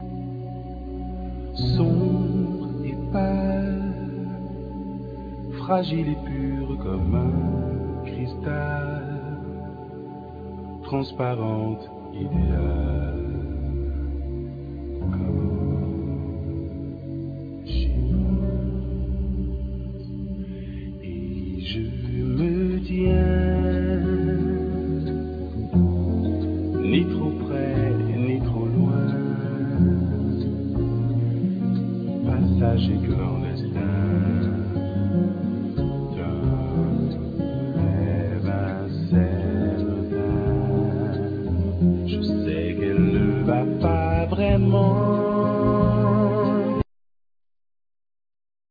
Guitar,Harmonica,Programing,Guitar Synthe
Drums
Bandneon
Vocal
Bass
Keyboards
Piano
DoubleBass
Percussions